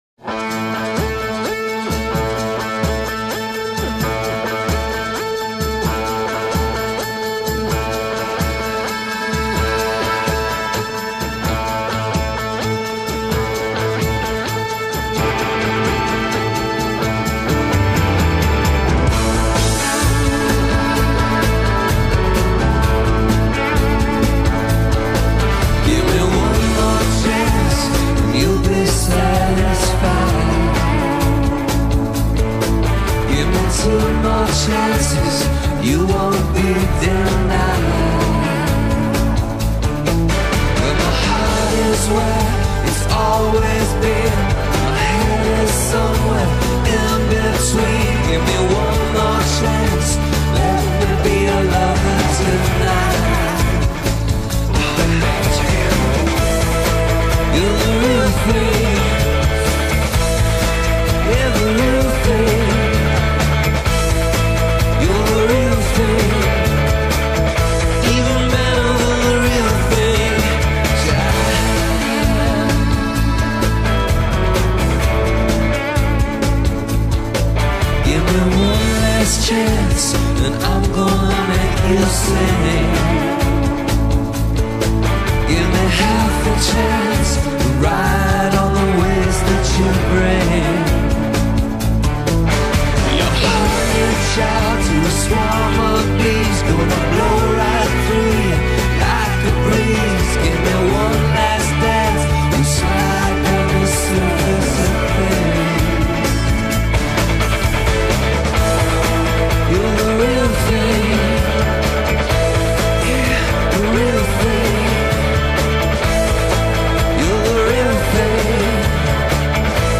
Rock, Pop Rock, Alternative Rock